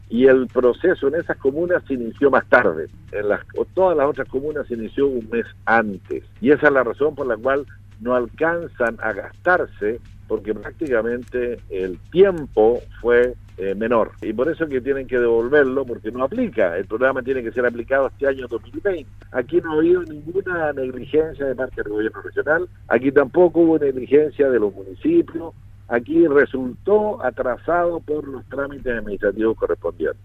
En entrevista con Radio Sago, el intendente de la región de Los Lagos, Harry Jurgensen, se refirió a las dificultades que tuvo el programa pro empleo en ocho municipios, quienes tuvieron que devolver parte de los montos otorgados para apoyar a la comunidad con trabajos de media jornada en medio de la pandemia.